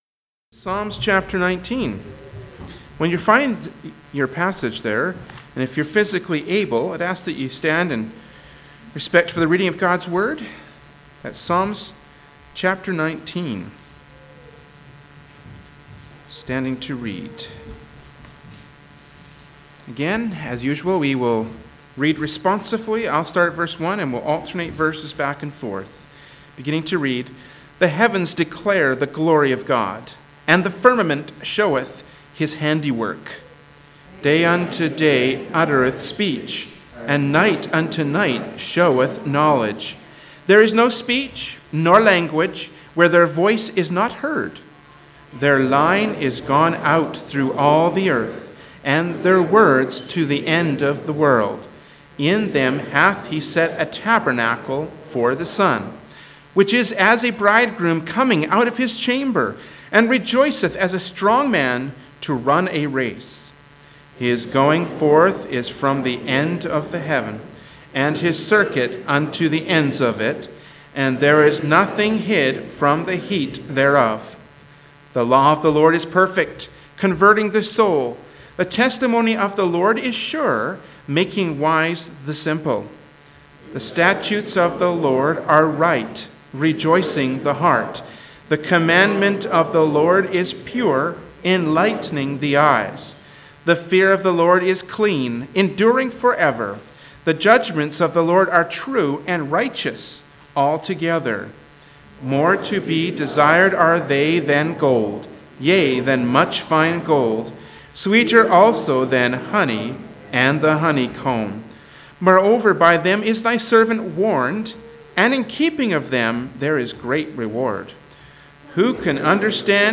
Genre: Preaching.
Passage: Psalms 19 Service Type: Sunday Morning Worship Service